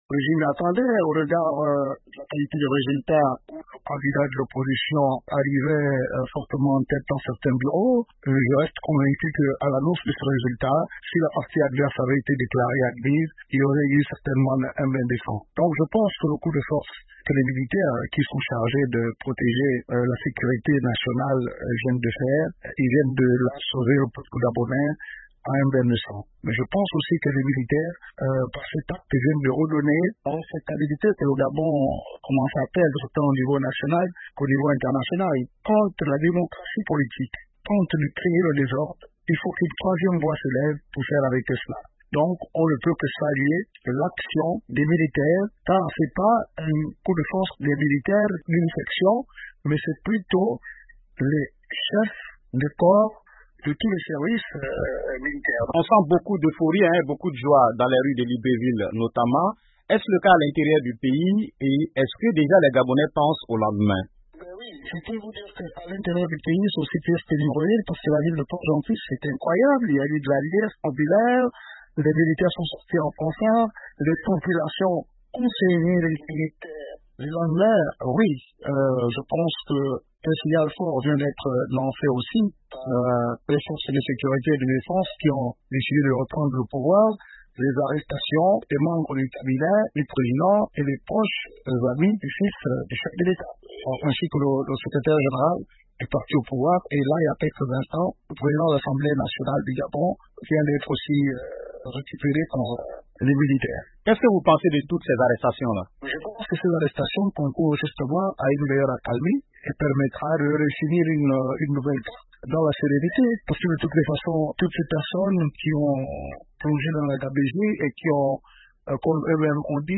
Ecoutez ces quelques réactions recueillies par notre correspondant